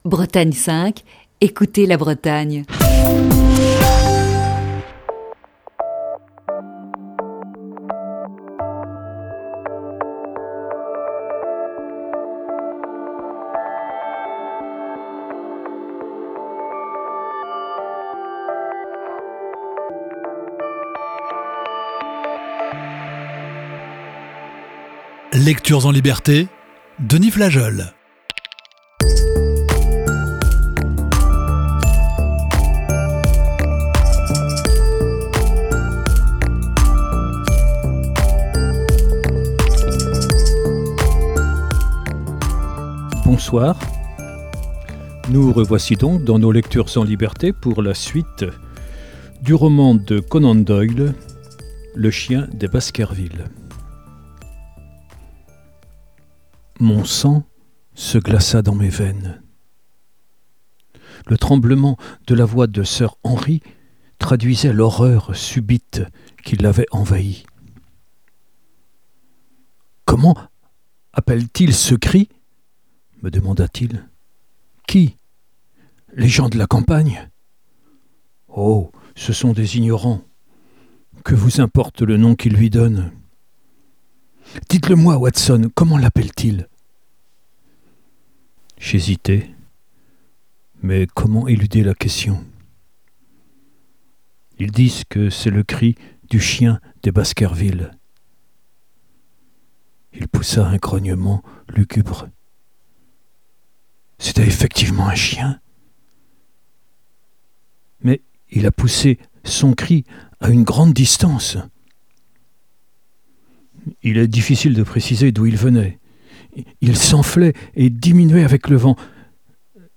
la lecture d'un classique de la littérature